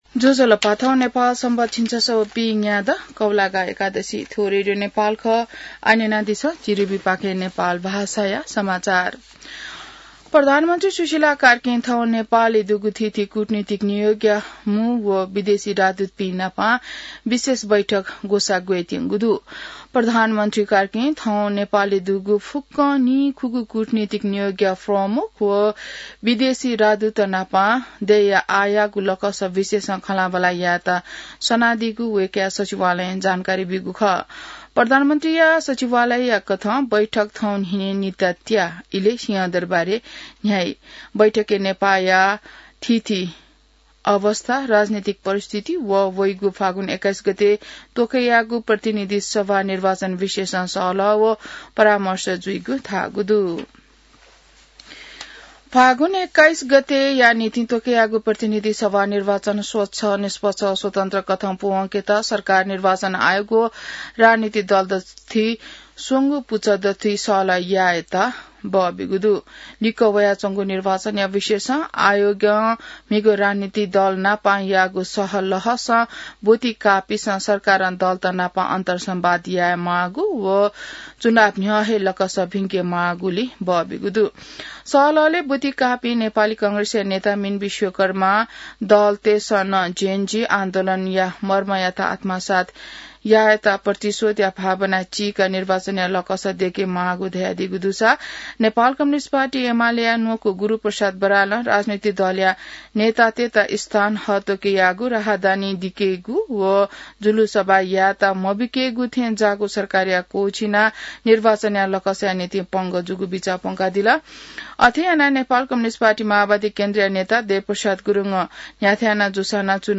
नेपाल भाषामा समाचार : ३१ असोज , २०८२